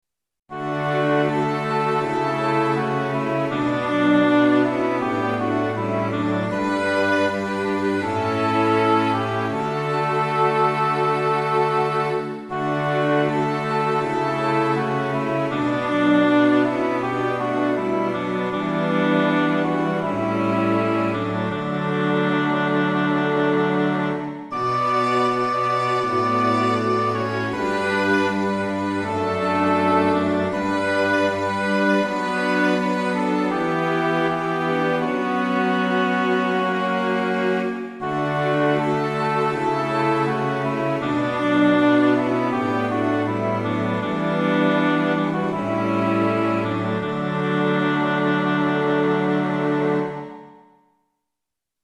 ♪聖歌隊練習補助
Tonality = D
Pitch = 440
♪   オーボエ フルート クラリネット トロンボーン